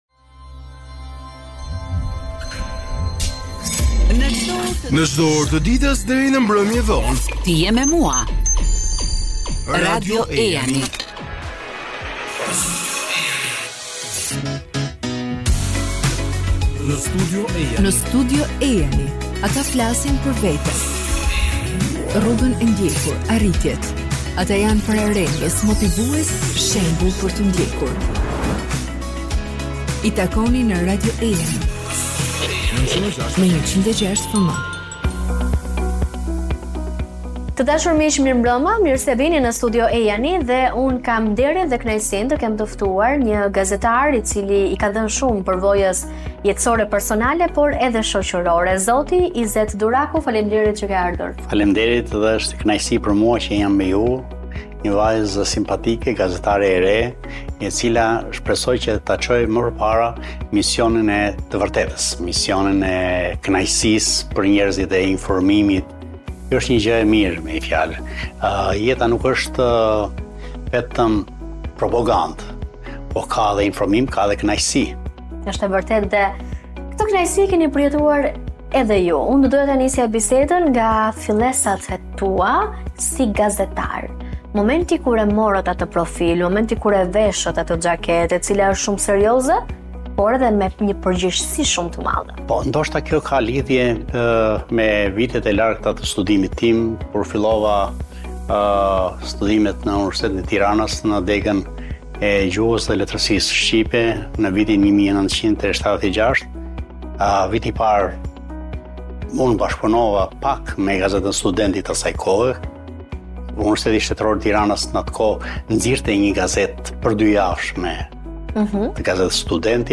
intervistë